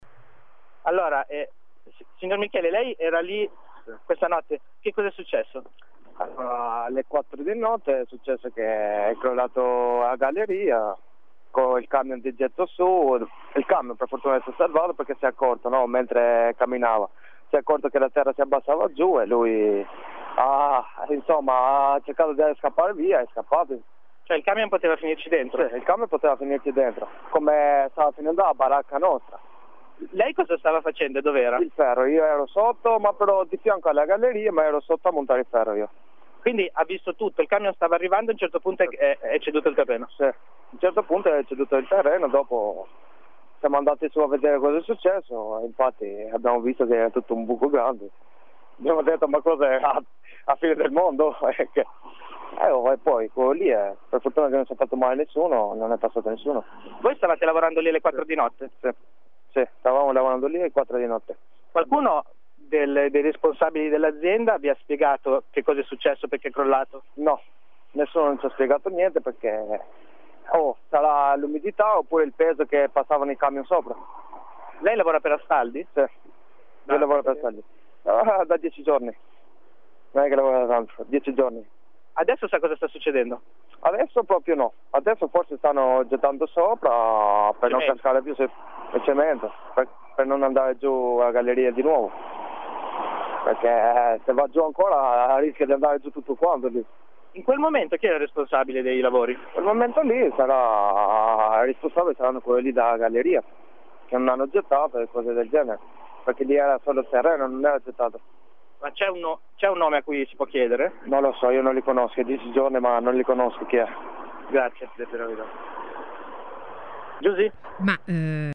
La testimonianza oculare